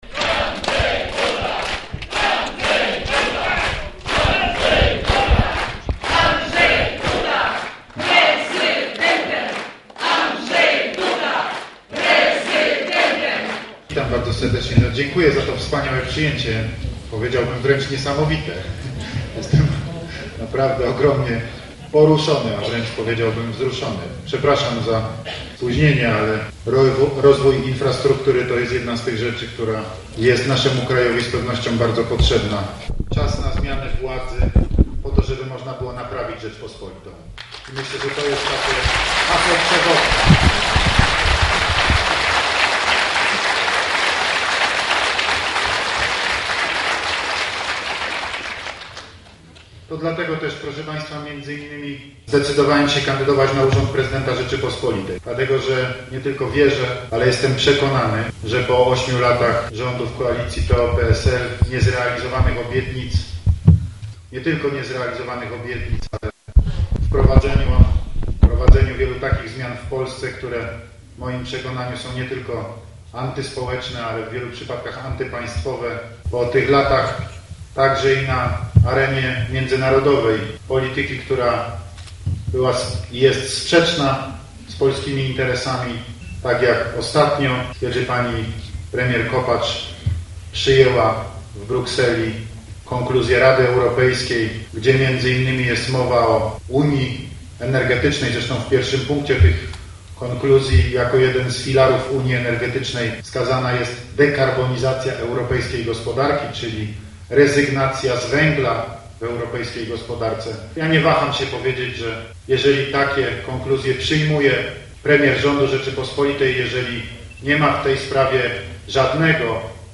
Prawie godzinę trwało przemówienie Andrzeja Dudy w szczelnie wypełnionej sali Centrum Kultury Muza w Lubinie. Kandydat Prawa i Sprawiedliwości w wyborach prezydenckich mówił o potrzebie daleko idących zmian niemal w każdej dziedzinie życia społecznego w Polsce.